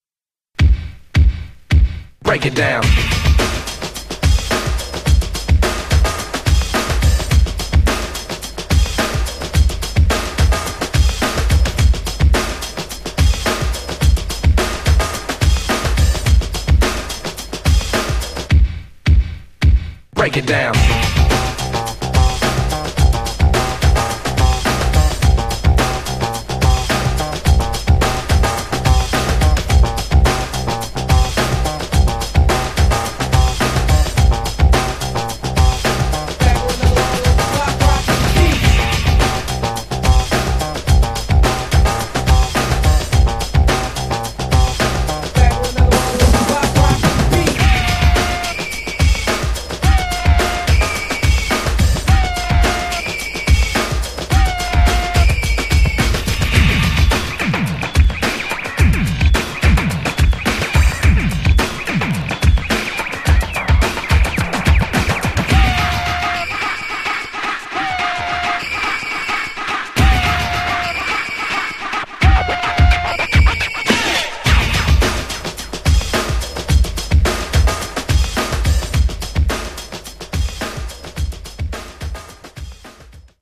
107 bpm
Clean Version